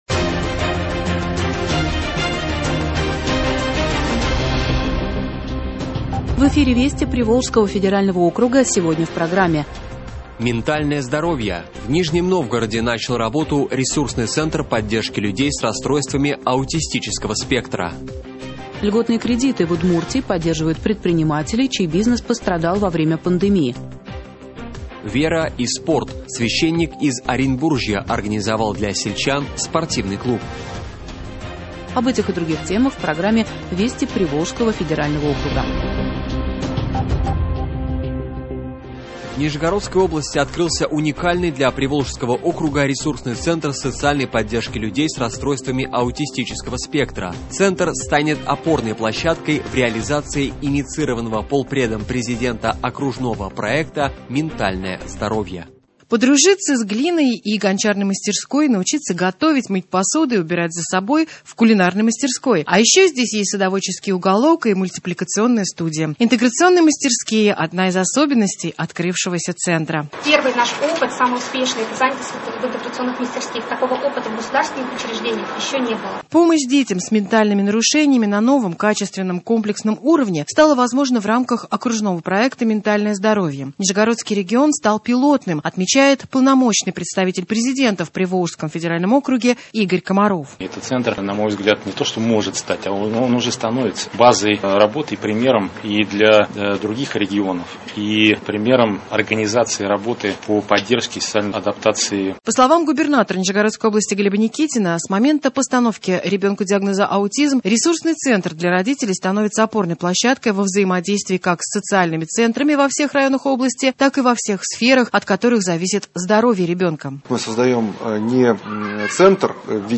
Радиообзор событий регионов ПФО.